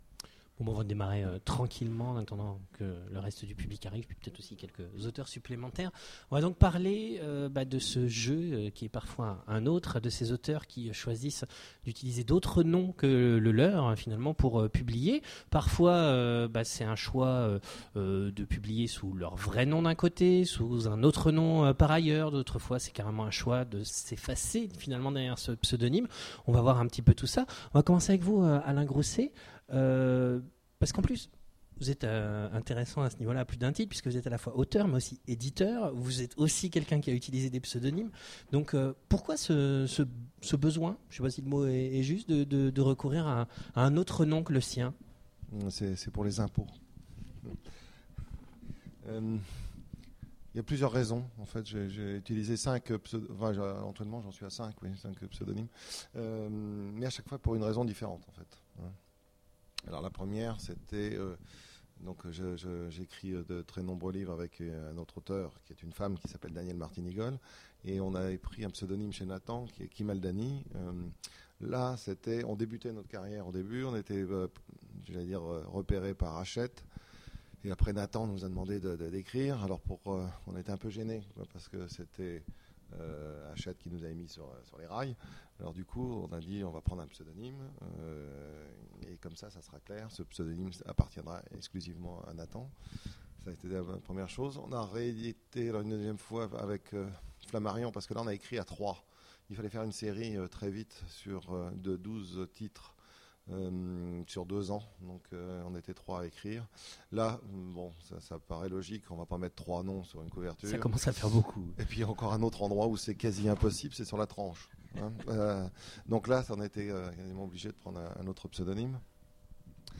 Conférence Imaginales 2010 : Je est un autre… Les écrivains et leur pseudonyme.
Voici l'enregistrement de la conférence « Je » est un autre… Les écrivains et leur pseudonyme aux Imaginales 2